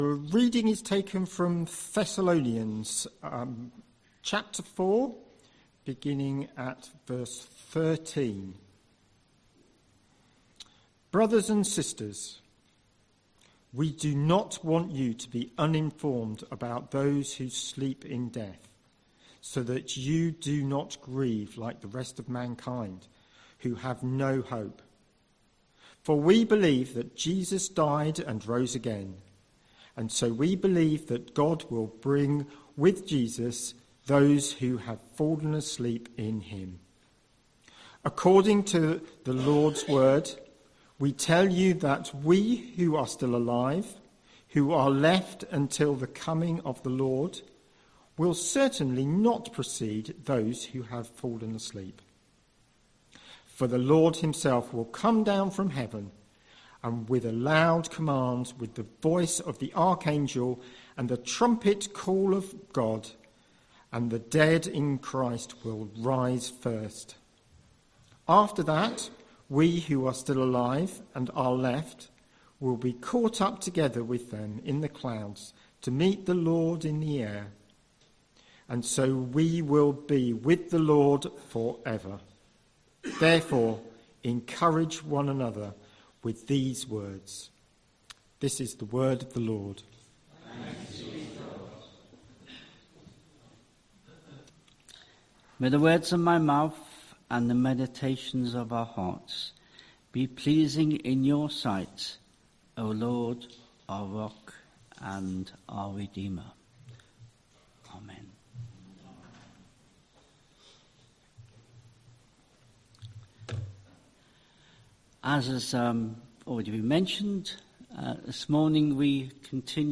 19th October 2025 Sunday Reading and Talk - St Luke's